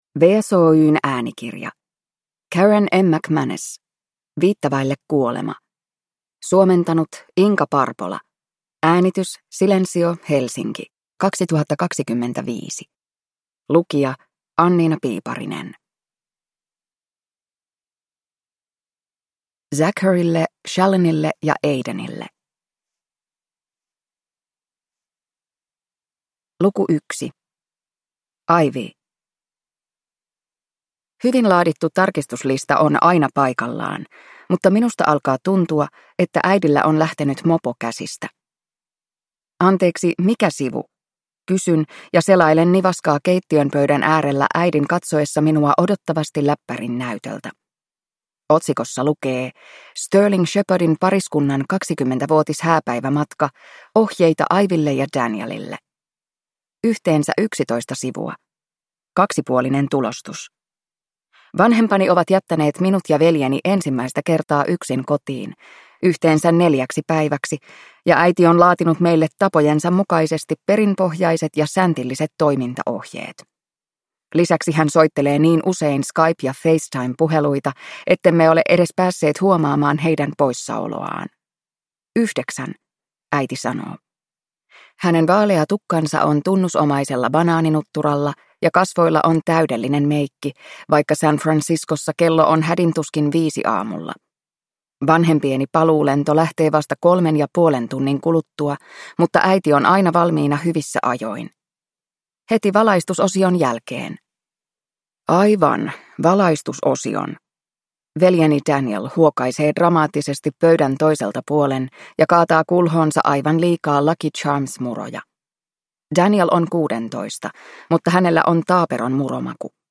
Viittä vaille kuolema – Ljudbok